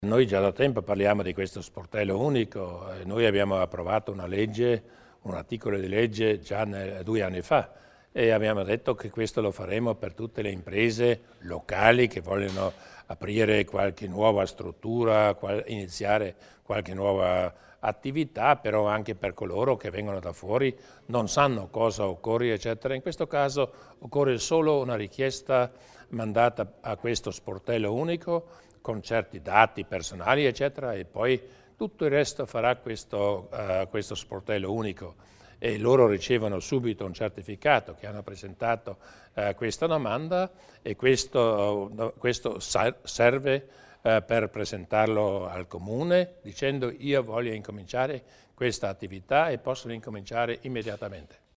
Il Presidente Durnwalder spiega l'importanza dello sportello unico